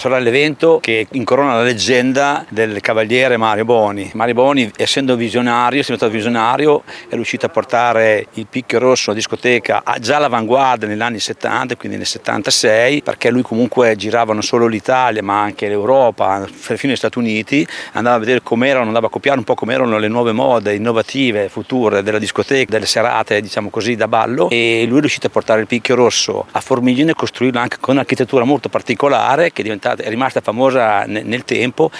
L’assessore comunale di Formigine Corrado Bizzini